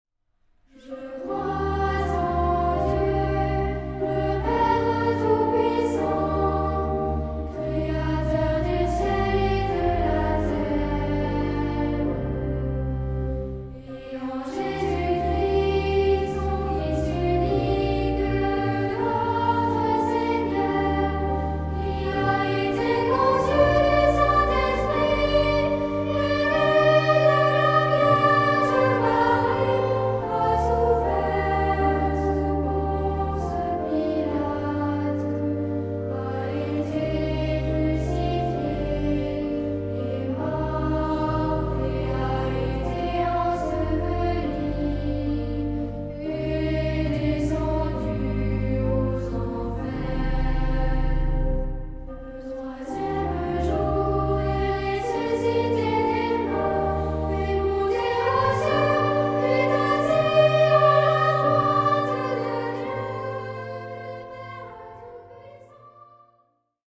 Tonality: C minor